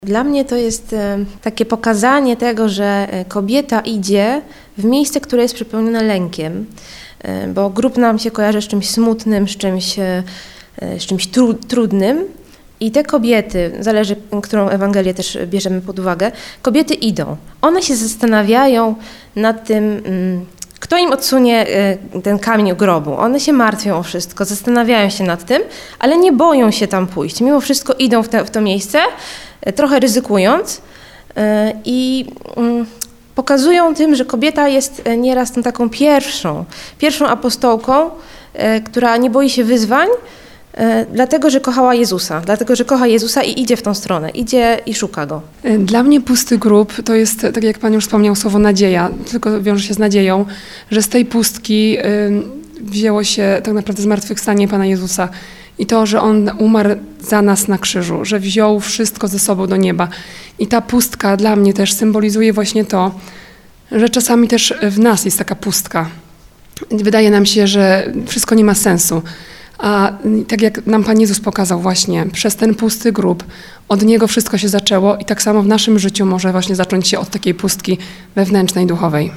Rozmawialiśmy o tym z przedstawicielkami wspólnoty Perły Maryi działającej przy parafii św. Jana Pawła II.